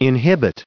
added pronounciation and merriam webster audio
437_inhibit.ogg